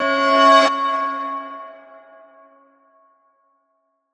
Key-bell_98.1.1.wav